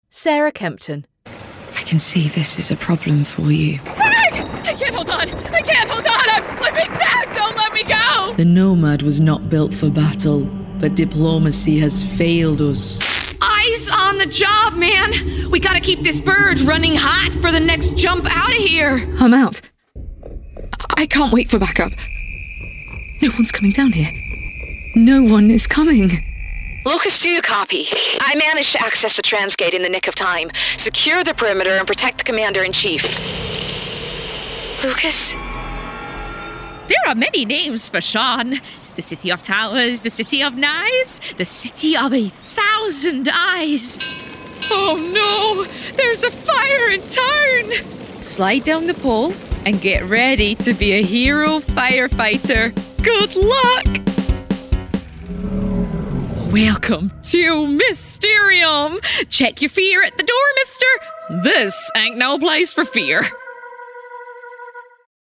English (British) voice over
Captivate your audience with a modern voice that's sophisticated, playful, and authentically British.